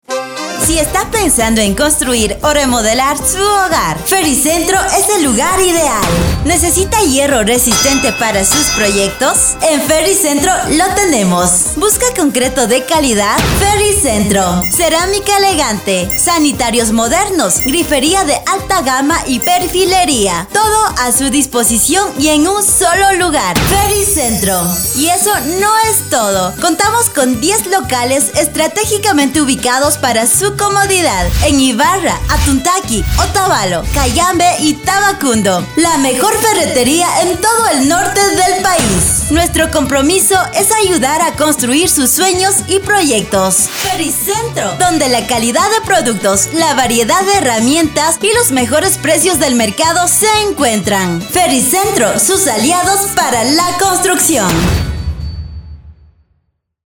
Grabación y Producción de cuñas | Radio Ilumán
En Radio Ilumán te ofrecemos el servicio profesional de grabación de cuñas radiales en dos idiomas: kichwa y castellano, con locutores y locutoras que comunican con autenticidad, claridad y cercanía a la audiencia.